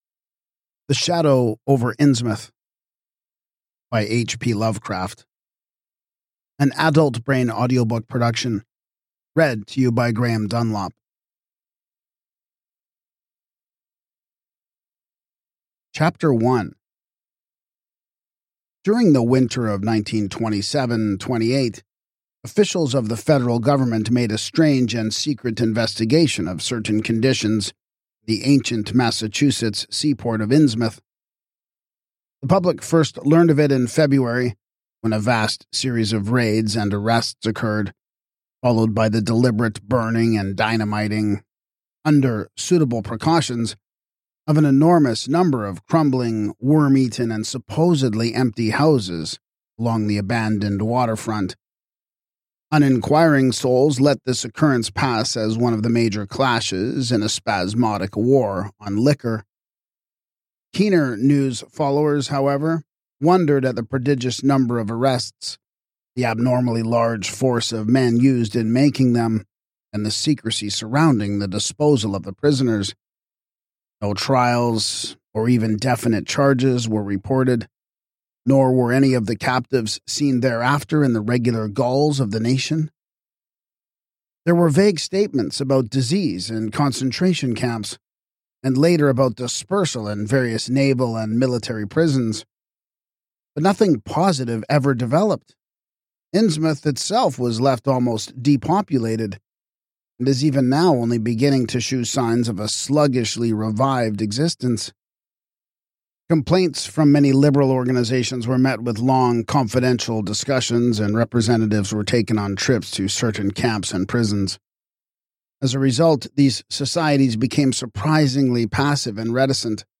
Podcast (audiobooks): Play in new window | Download
🎙 Why You’ll Love This Audiobook Atmospheric and Dread-Filled: Lovecraft’s most accessible and cinematic tale, brought to life through vivid narration and immersive detail.